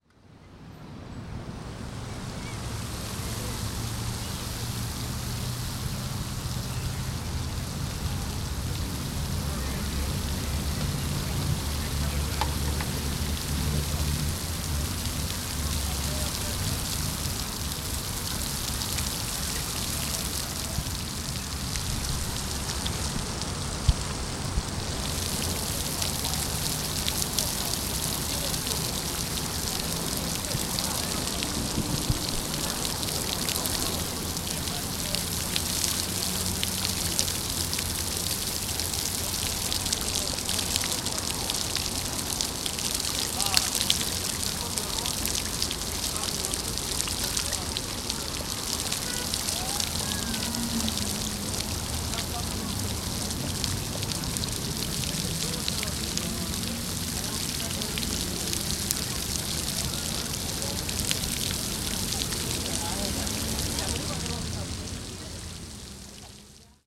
Звуки фонтана
Шум танцующего городского фонтана в парке, воплощающего водные брызги